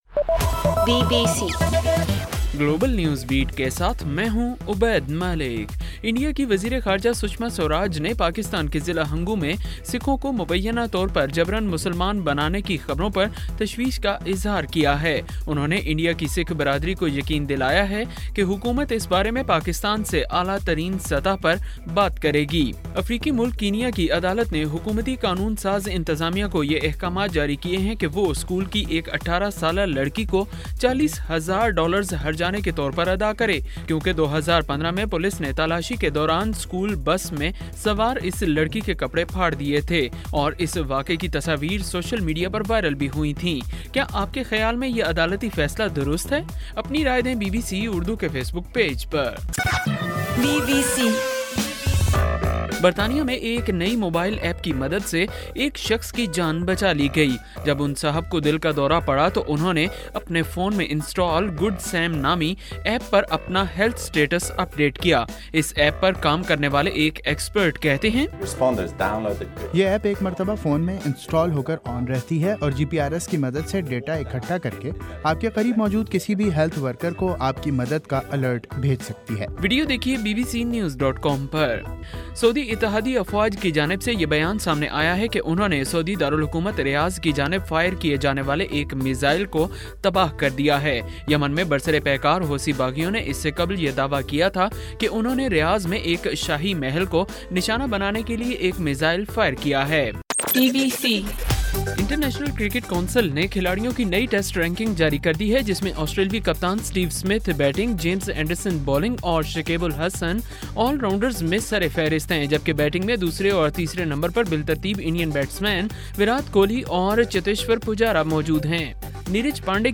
گلوبل نیوز بیٹ بُلیٹن اُردو زبان میں رات 8 بجے سے صبح 1 بجے تک ہر گھنٹےکے بعد اپنا اور آواز ایفایم ریڈیو سٹیشن کے علاوہ ٹوئٹر، فیس بُک اور آڈیو بوم پر ضرور سنیے